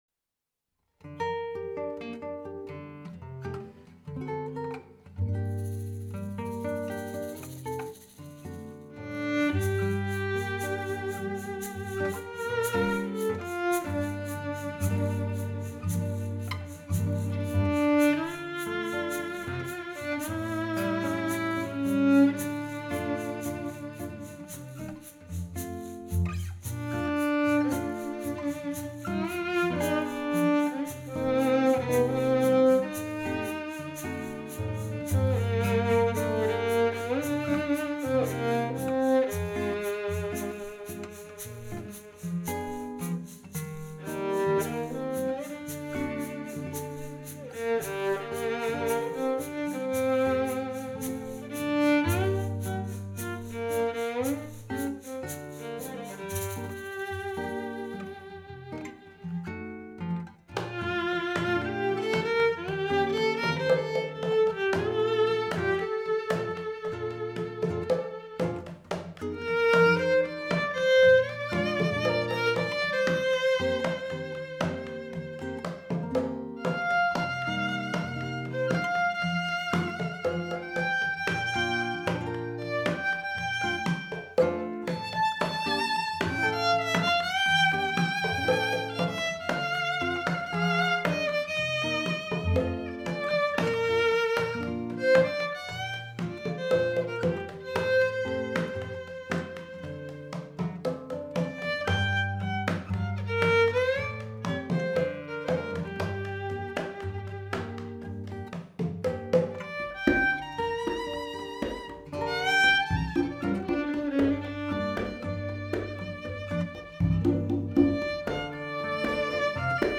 Music from South America and Spain.
Percussion.
Category: Latin
For percussion we employed a single Rode NT1000.